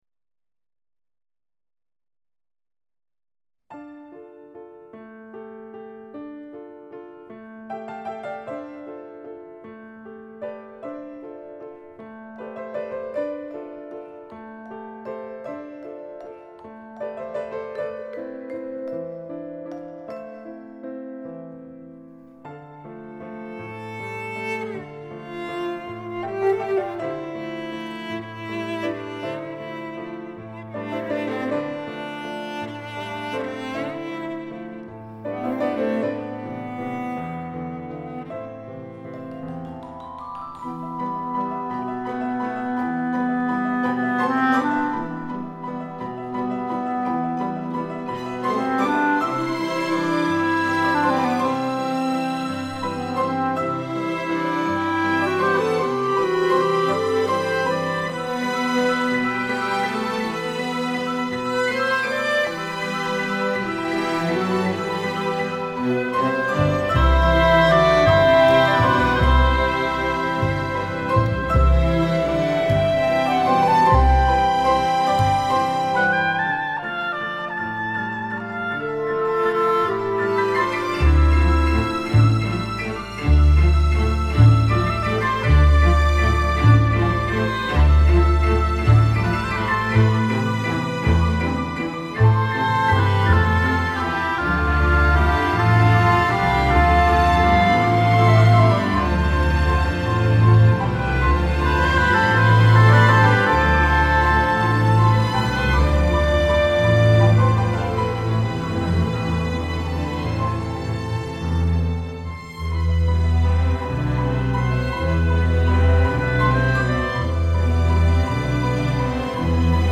Ορχηστρική μουσική